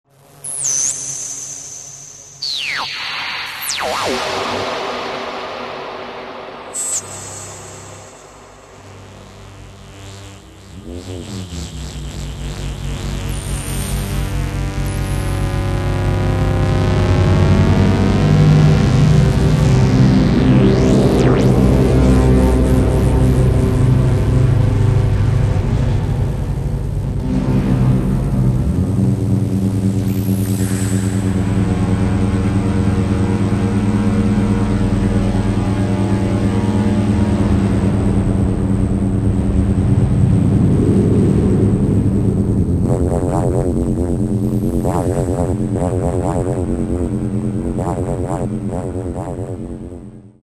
Електроакустичні досліди